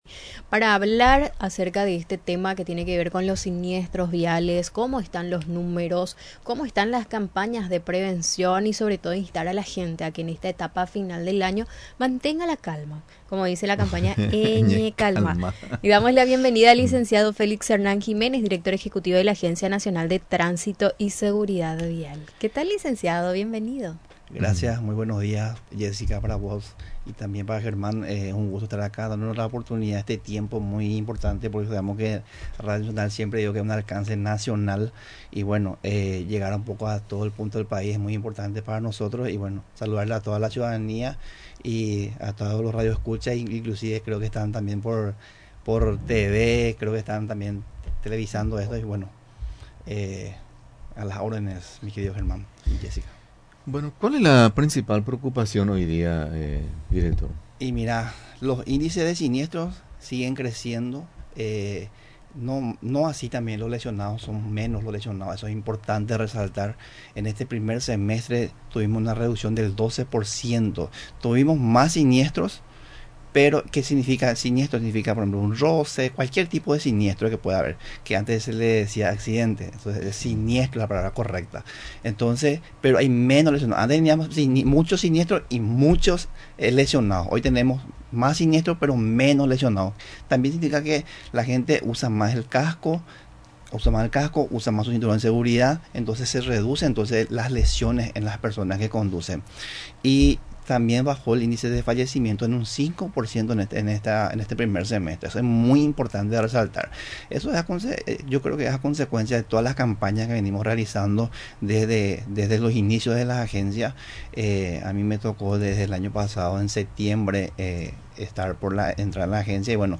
El director ejecutivo de la Agencia Nacional de Tránsito y Seguridad Vial, Félix Hernán Jiménez, durante su visita a los estudios de Radio Nacional del Paraguay, hizo hincapié en la necesidad de que la ciudadanía mantenga la calma, promoviendo la campaña denominada «Eñecalmá» especialmente durante la etapa final de año.